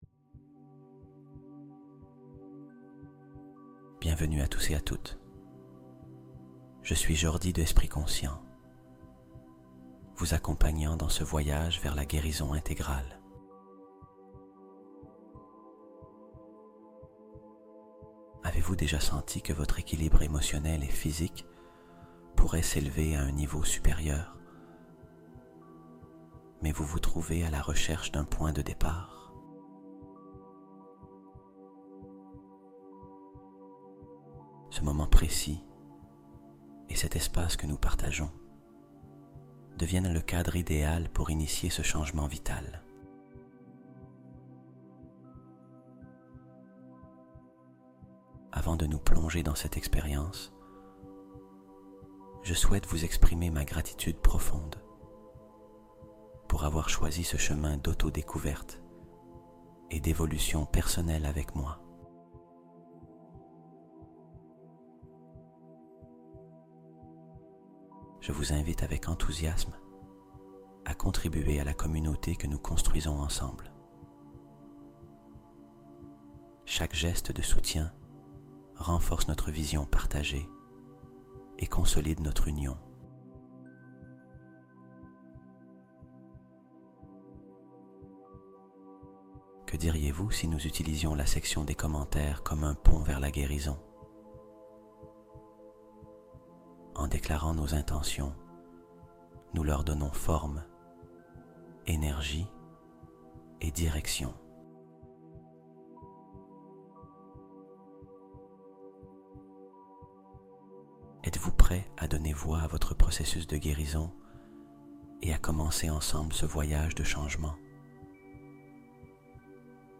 Guérison quantique instantanée | Hypnose pour sommeil réparateur et soulagement total